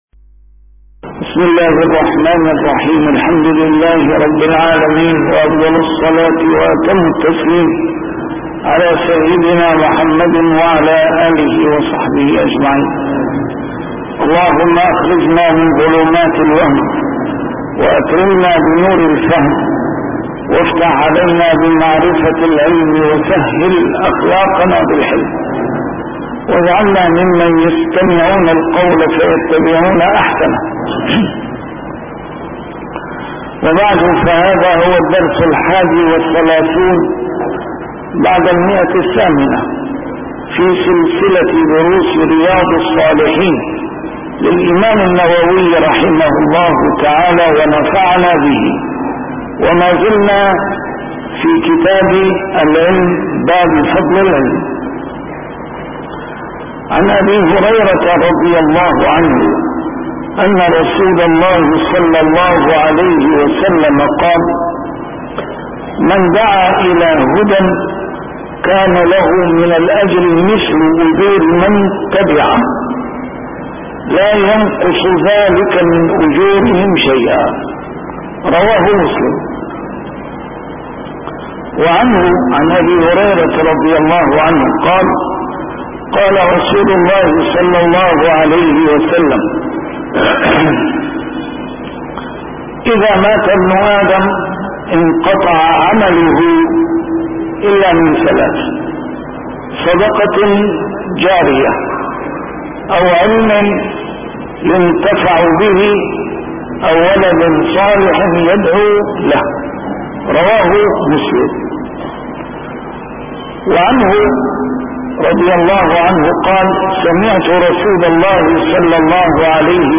A MARTYR SCHOLAR: IMAM MUHAMMAD SAEED RAMADAN AL-BOUTI - الدروس العلمية - شرح كتاب رياض الصالحين - 831- شرح رياض الصالحين: فضل العلم